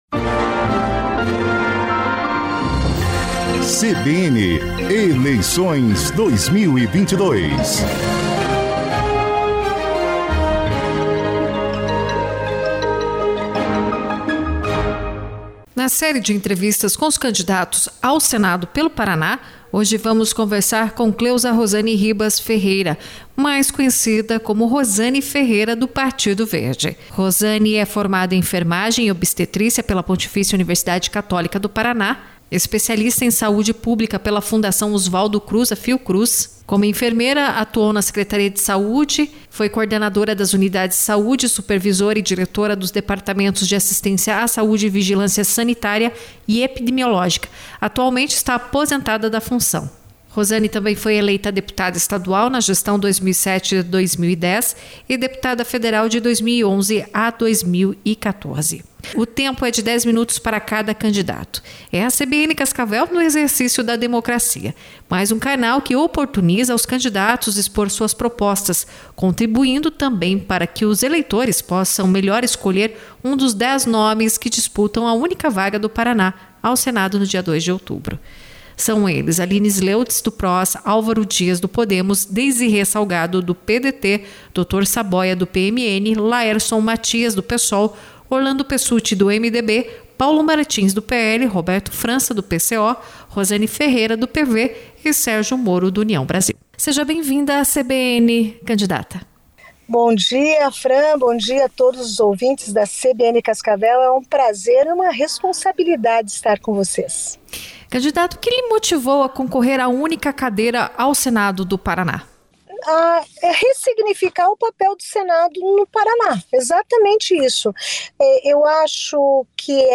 Na série de entrevistas com os candidatos ao Senado pelo Paraná, a CBN Cascavel ouviu nesta terça-feira (20) Rosane Ferreira.